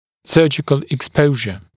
[‘sɜːʤɪkl ɪk’spəuʒə][‘сё:джикл ик’споужэ]хирургическое обнажение (напр. коронки ретинированного клыка)